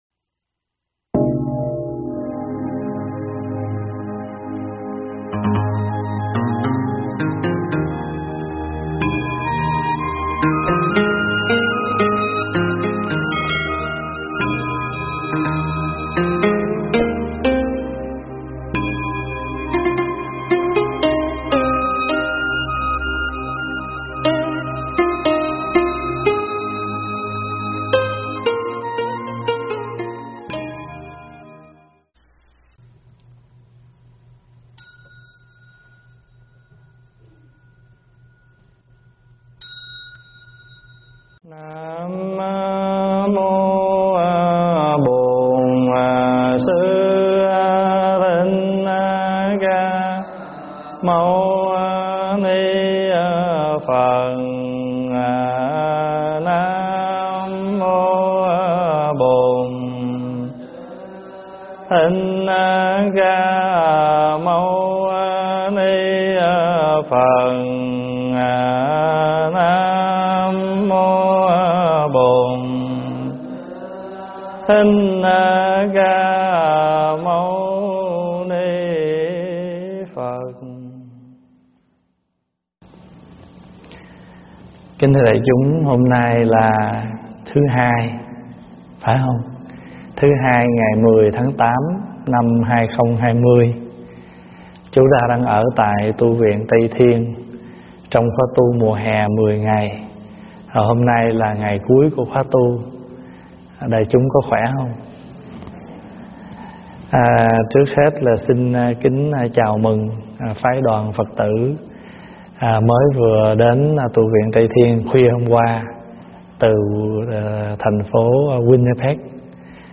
Nghe Mp3 thuyết pháp Bảy Phật Dược Sư